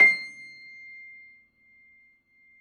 53h-pno22-C5.aif